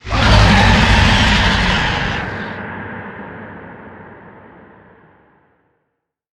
Sfx_creature_squidshark_callout_02.ogg